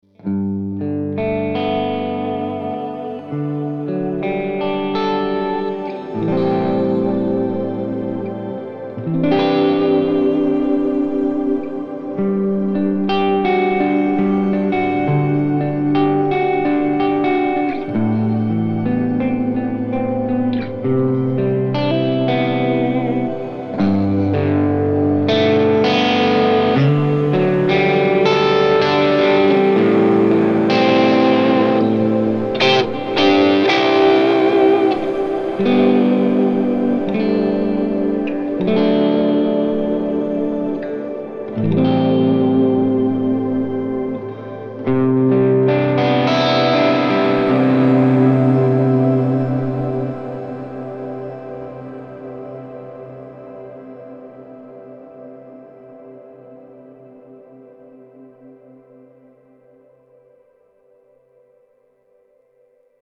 This Amp Clone rig pack is made from a Fuchs ODS 50 amp.
IR USED: MARSHALL 1960A V30 SM57+ E906 POS 1
RAW AUDIO CLIPS ONLY, NO POST-PROCESSING EFFECTS